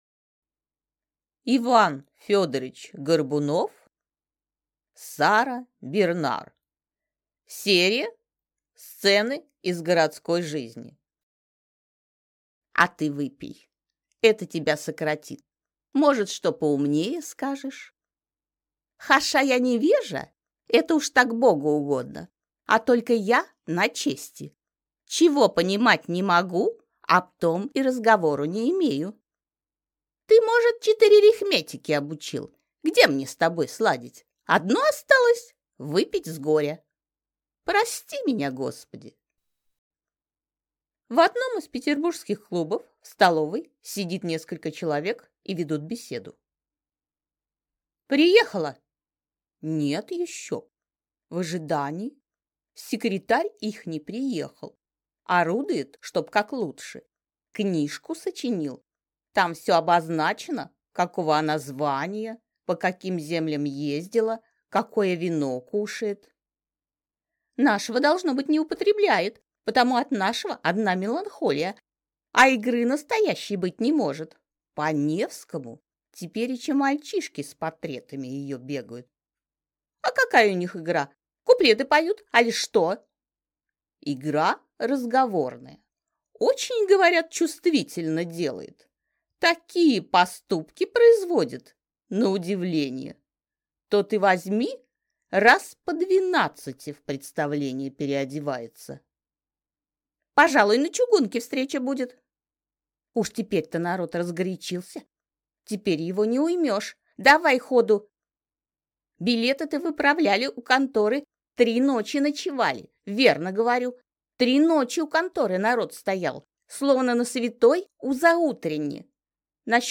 Аудиокнига Сара Бернар | Библиотека аудиокниг